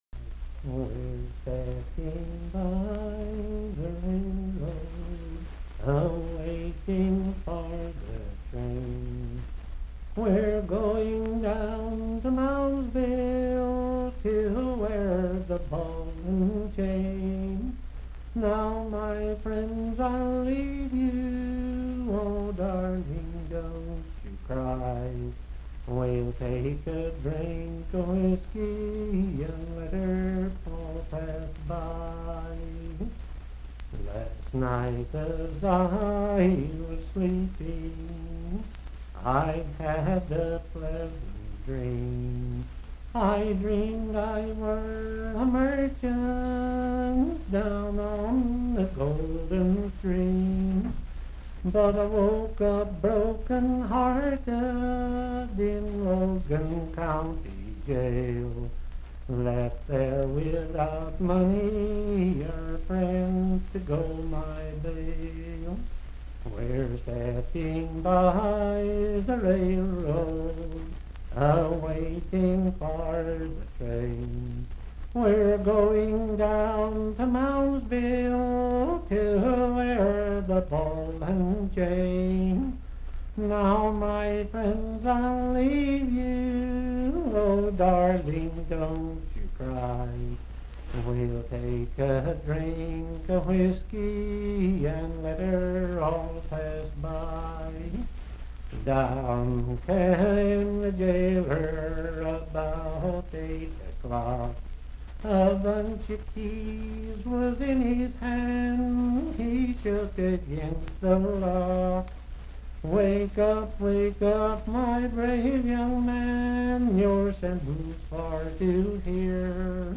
Unaccompanied vocal music
in Dryfork, WV
Voice (sung)
Randolph County (W. Va.)